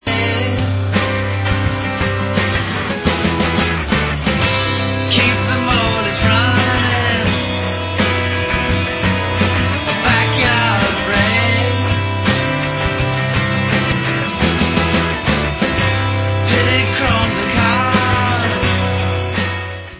..SCUFFED BEAUTY & ASTONISHING ORIGINALITY(NME)
sledovat novinky v kategorii Pop